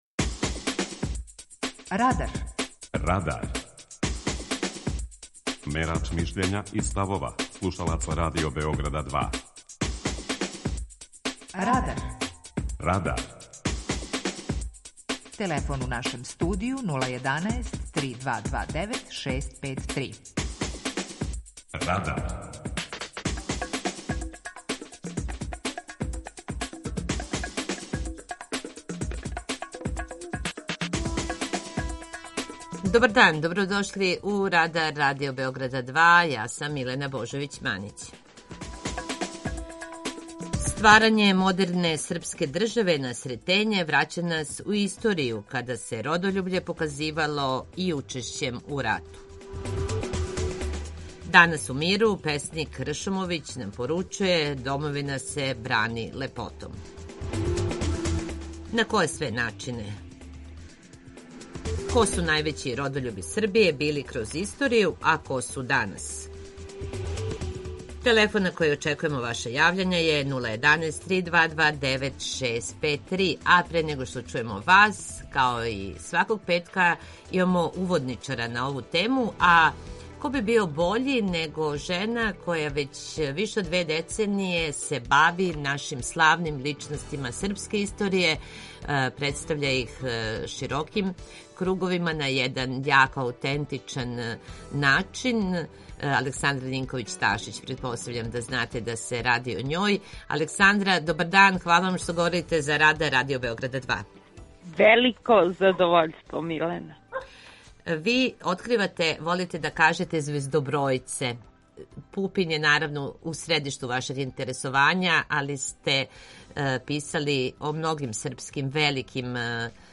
Питање Радара: Шта је данас родољубље? преузми : 19.16 MB Радар Autor: Група аутора У емисији „Радар", гости и слушаоци разговарају о актуелним темама из друштвеног и културног живота.